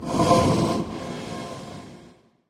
Minecraft.Client / Windows64Media / Sound / Minecraft / mob / blaze / breathe3.ogg
breathe3.ogg